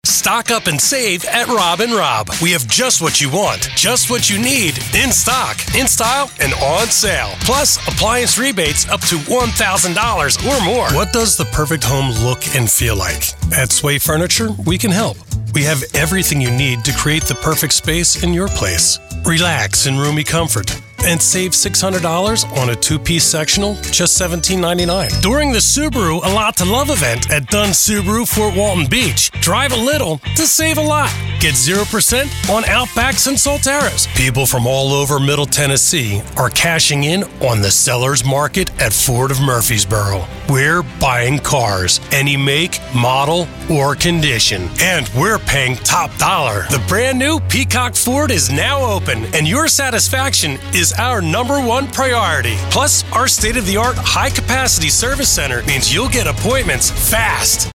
Male
Friendly, middle aged, father, confident, construction worker, professional, conversational, warm, authentic, engaging, deep, authentic, intimate, articulate, guy next door, informed, knowledgeable
Television Spots
Words that describe my voice are confident, middle aged, warm.